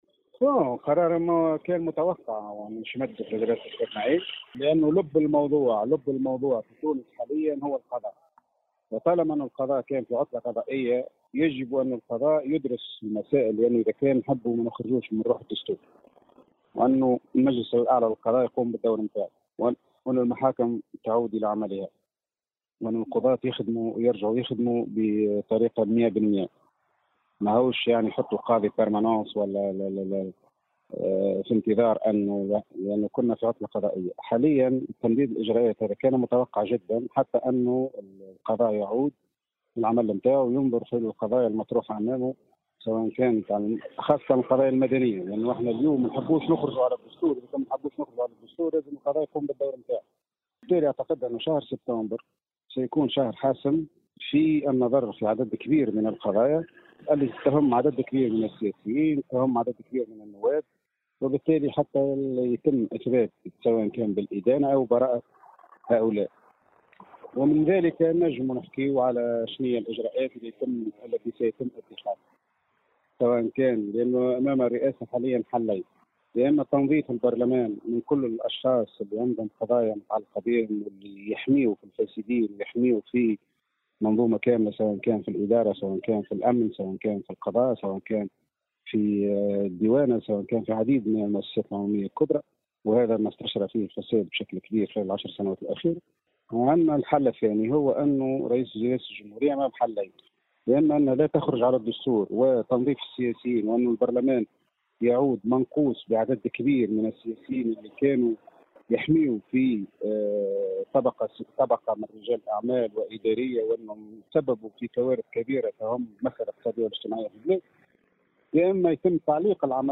Le représentant parlementaire, Mohamed Ammar a indiqué aujourd’hui dans une déclaration accordée à Tunisie Numérique que la décision du président de la République, Kais Saied relative à la prolongation des mesures exceptionnelles prises le 25 juillet 2021 était prévisible étant donné que les tribunaux sont fermés (période de vacances judiciaires).